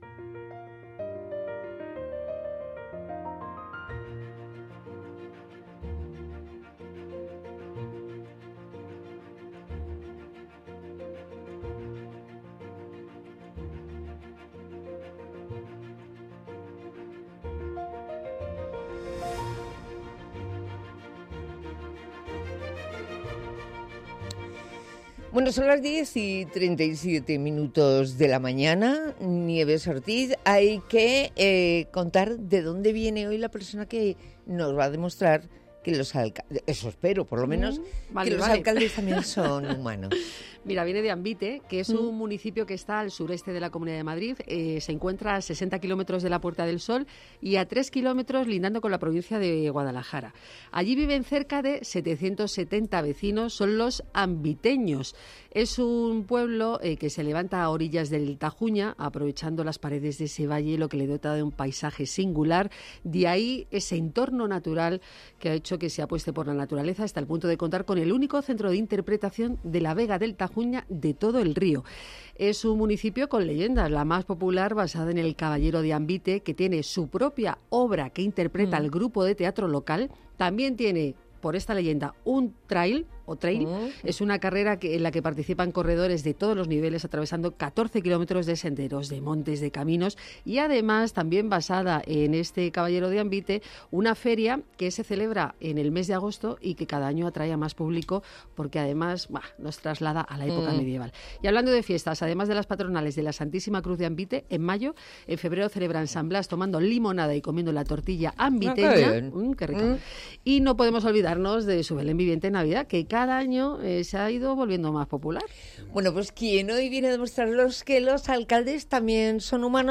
En la sección de Los alcaldes también son humanos, ha venido a demostrarlo a Onda Madrid el regidor de Ambite.
charla distendida